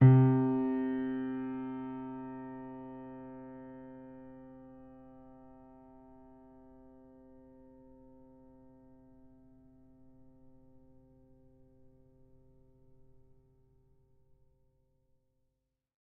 sampler example using salamander grand piano
C3.ogg